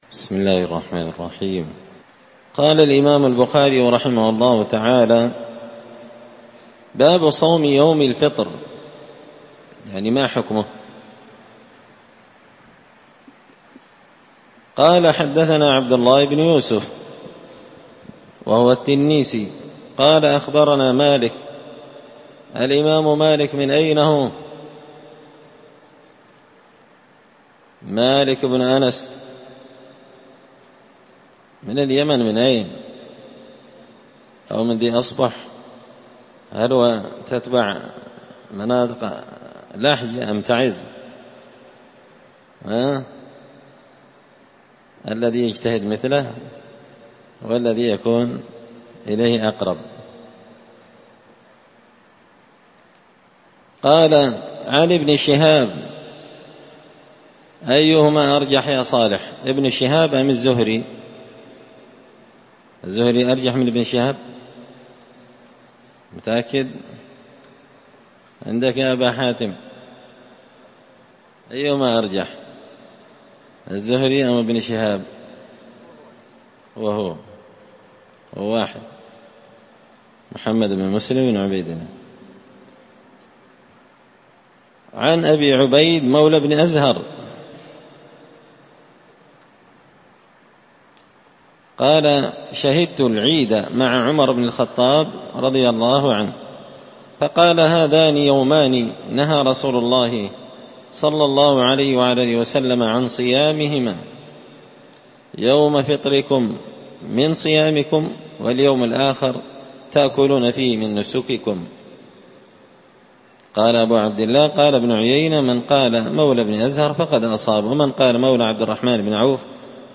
الدرس الحادي الخمسون (51) باب صوم يوم الفطر